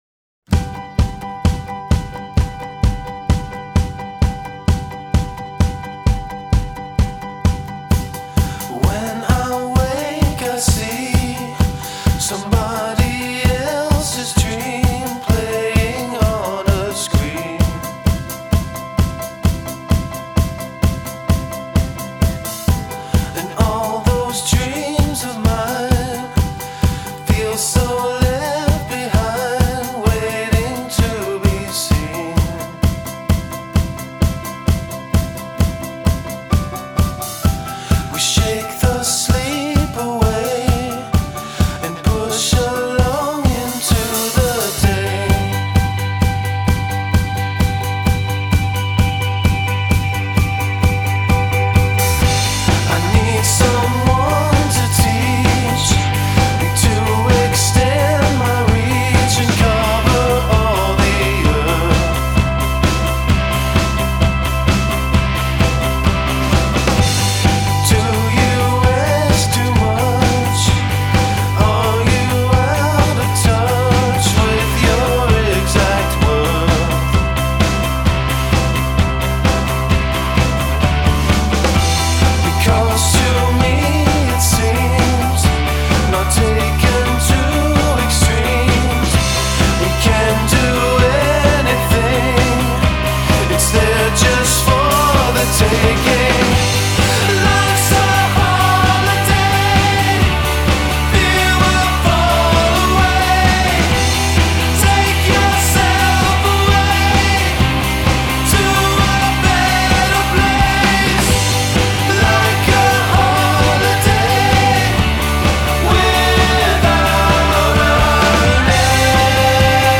Mid Tempo Mlvx, Full band